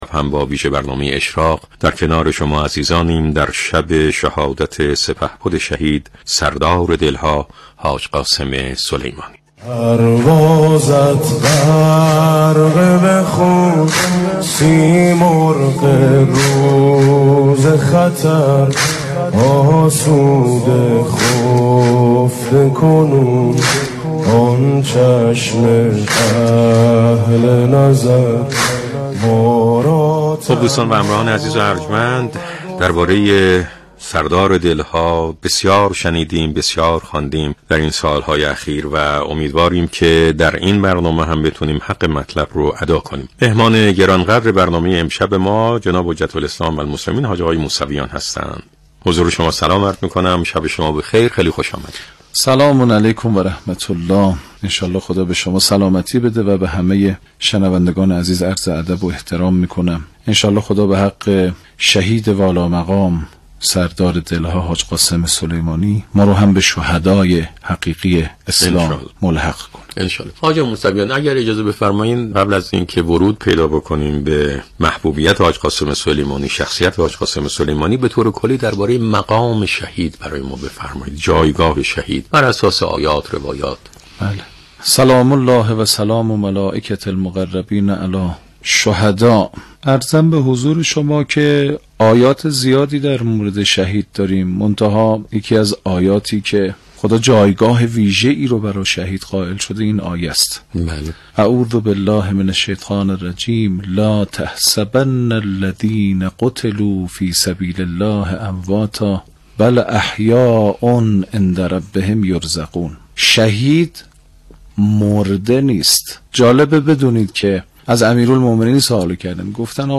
اشراق عنوان ویژه‌برنامه سالروز شهادت حاج قاسم سلیمانی شامگاه گذشته، 12 دی‌ماه از رادیو قرآن پخش شد.
ویژه برنامه شهادت قاسم سلیمانی در «اشراق»به گزارش ایکنا، سومین سالروز شهادت حاج قاسم سلیمانی در قالب برنامه «اشراق» شبکه رادیویی قرآن دوشنبه، 12 دی‌ماه، ساعت 21 به مدت 35 دقیقه پخش شد.